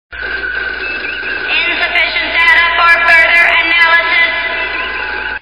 Category: Sound FX   Right: Personal